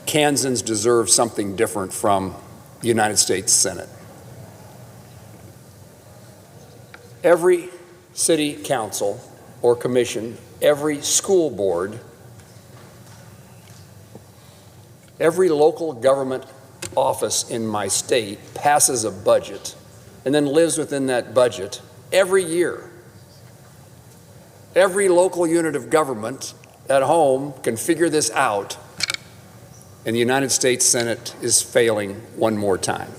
He didn’t name Democrats in his speech on the Senate floor, but he says some in Congress want to address other matters with the continuing resolution.